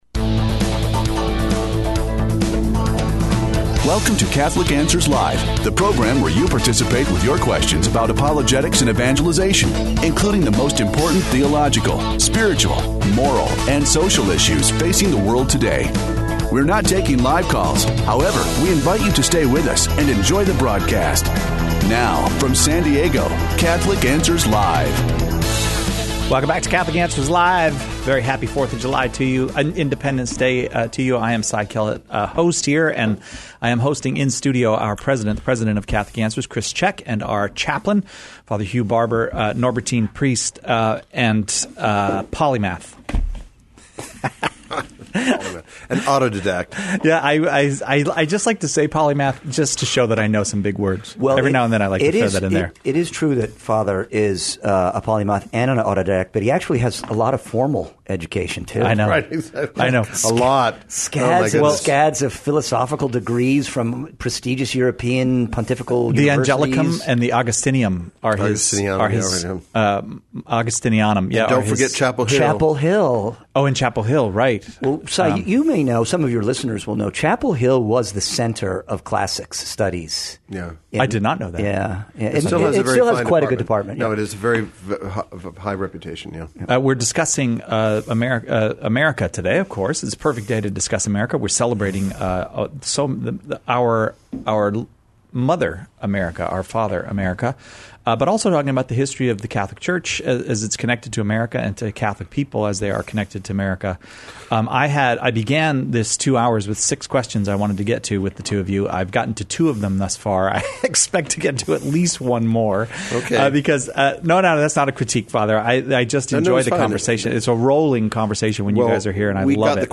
extended conversation